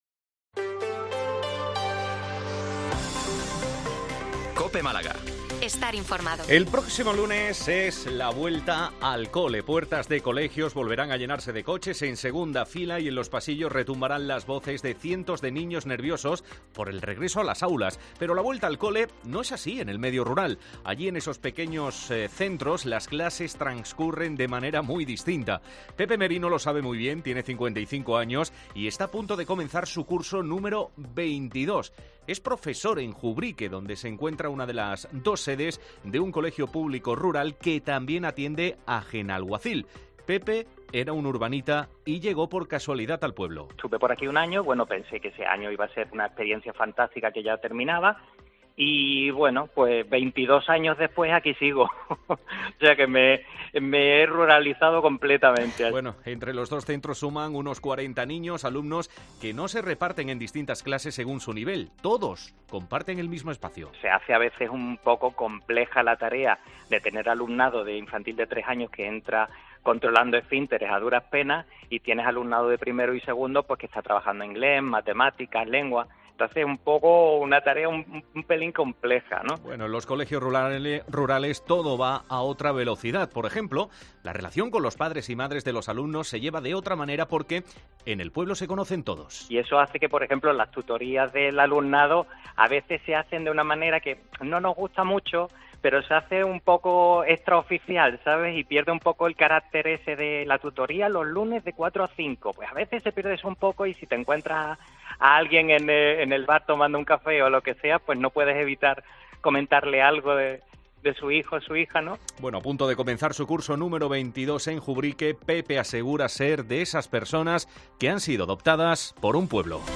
Informativo 14:20 Málaga 070923